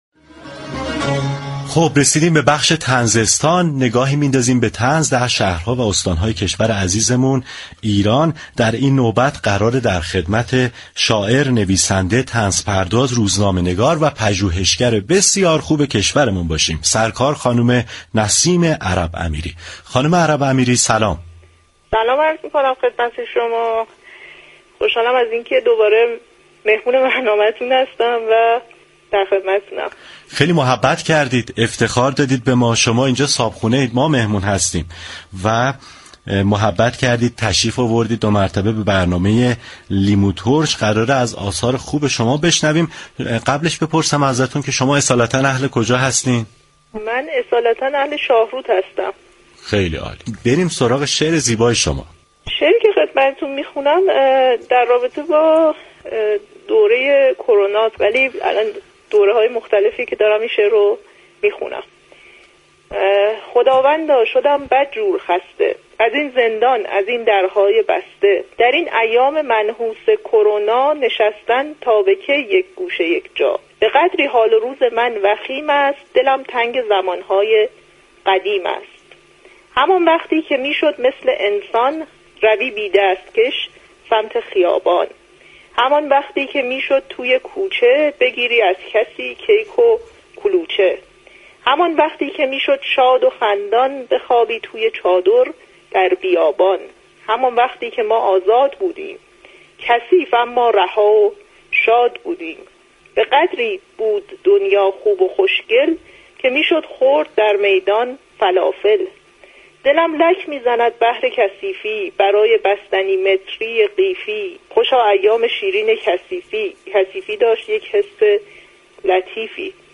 گفتگوی برنامه لیموترش